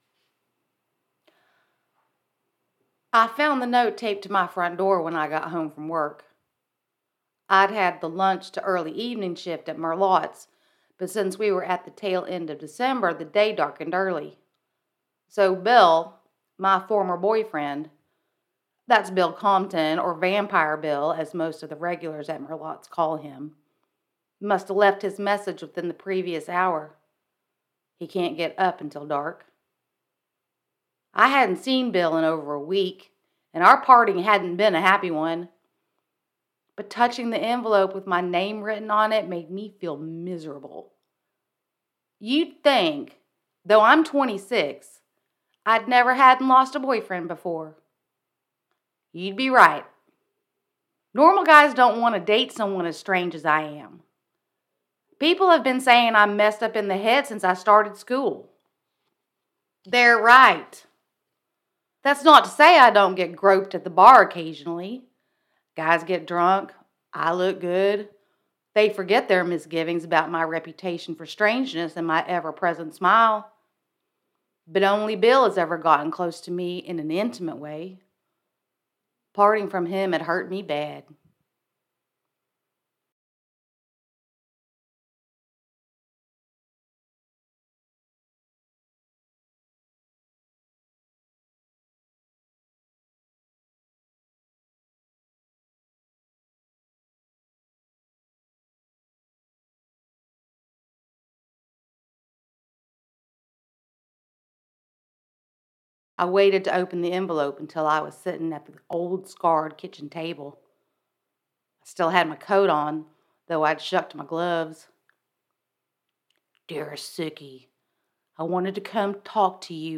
As an avid reader and writer, I devote time to launching words off the page and into hearts and minds by recording and producing custom narration for audiobooks.
Fiction, American Southern, Storyteller |